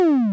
bullet2p.wav